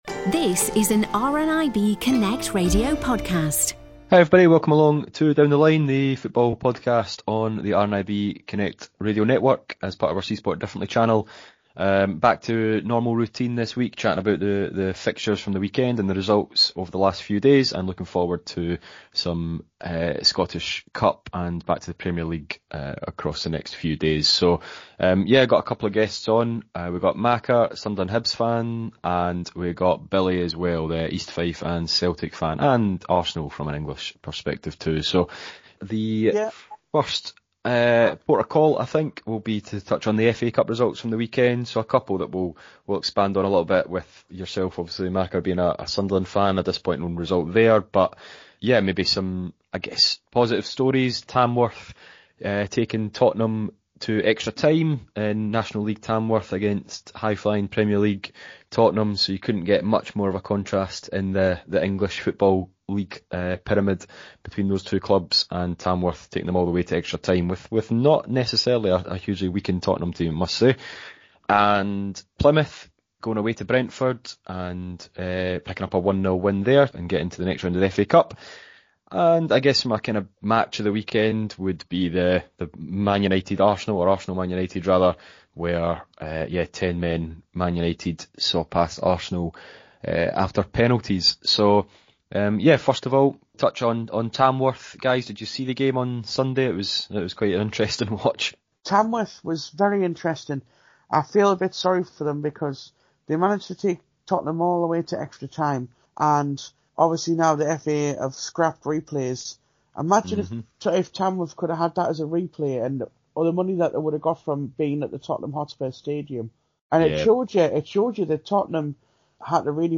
Football-loving members of RNIB ‘Community Connections’ telephone groups get together each week to talk about the latest going on in leagues across Scotland and England. This time, looking back at third-round FA Cup action, as well as a preview of next week’s fixtures.